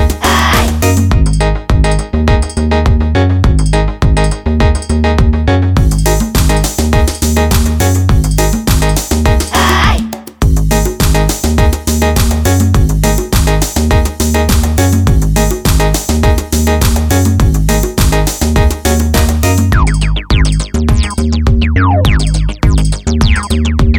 no Backing Vocals Dance 3:44 Buy £1.50